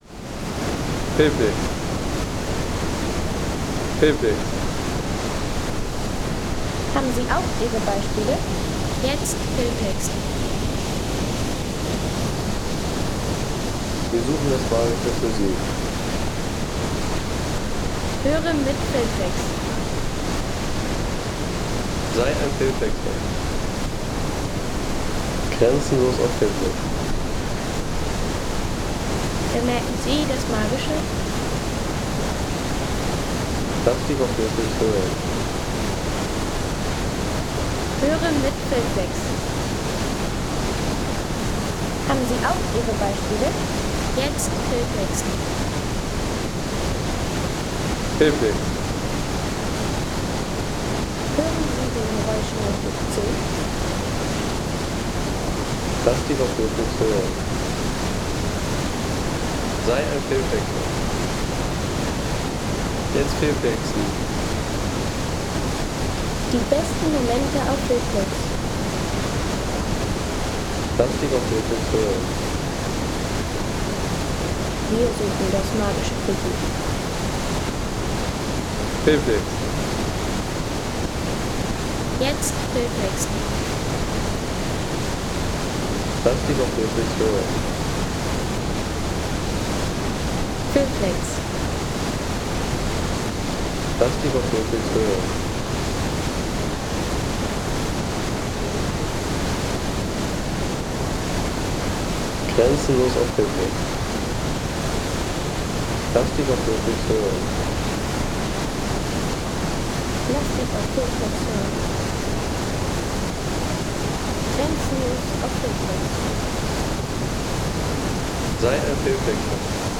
Erholungsgenuss für alle, wie z.B. diese Aufnahme: Gletscherfluss Isel Umbalfälle
Gletscherfluss Isel Umbalfälle